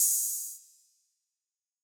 Metro Openhats [Thin].wav